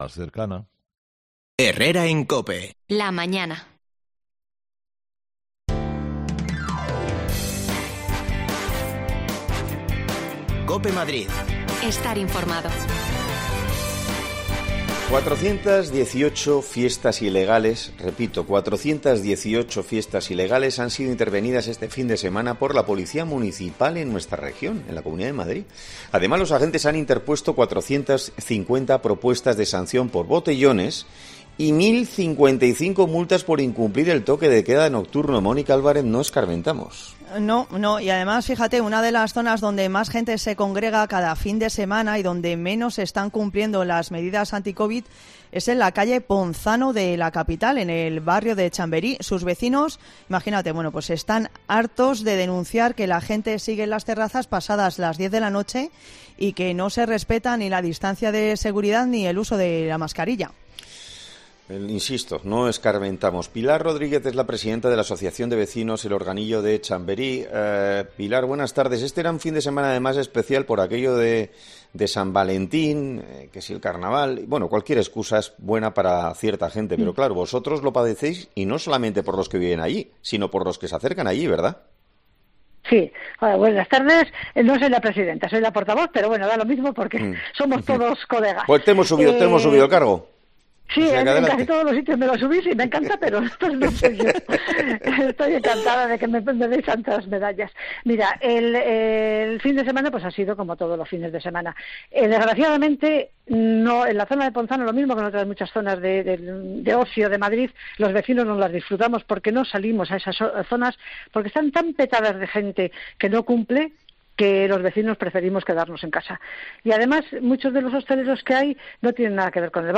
Hablamos con sus vecinos